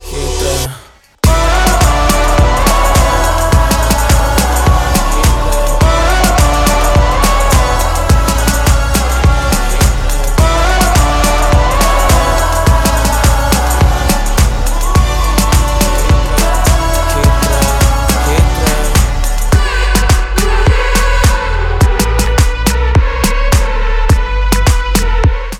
поп
атмосферные
мелодичные
Мелодичный проигрыш